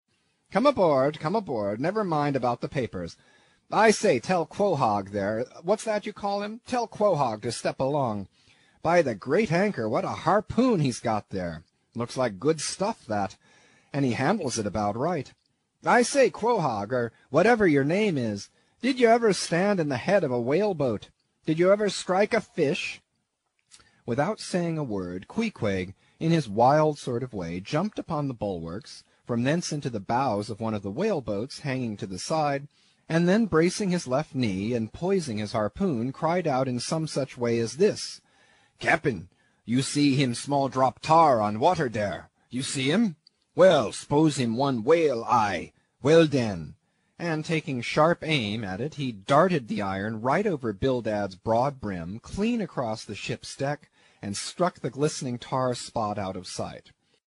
英语听书《白鲸记》第98期 听力文件下载—在线英语听力室